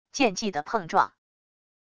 剑技的碰撞wav音频